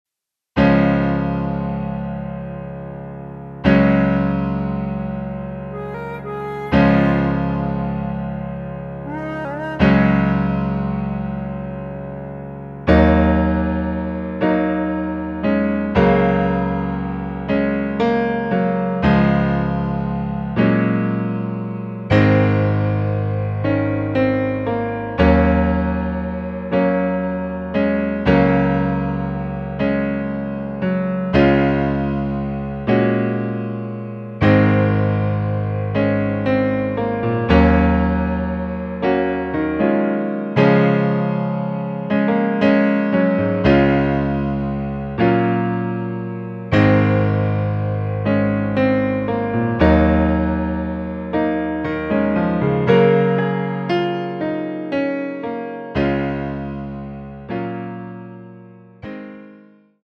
C#
앞부분30초, 뒷부분30초씩 편집해서 올려 드리고 있습니다.
중간에 음이 끈어지고 다시 나오는 이유는